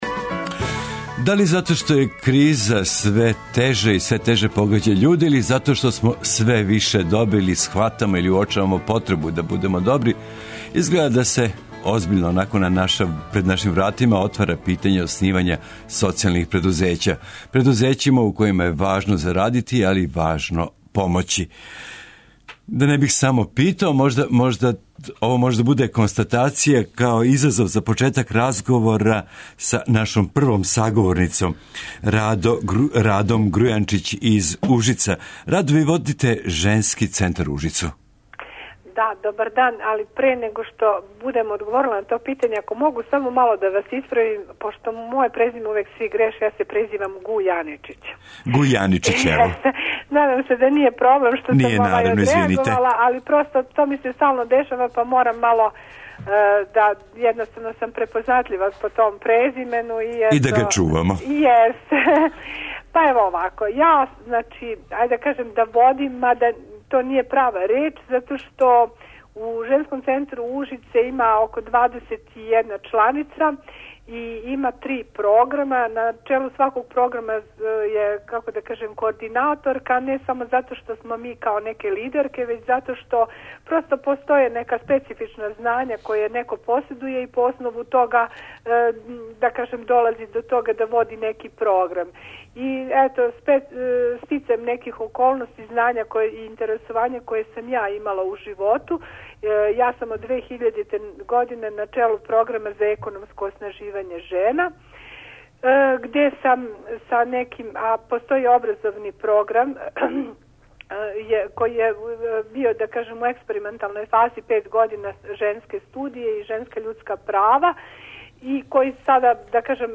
О томе ће нам говорити људи који воде таква предузећа широм Србије.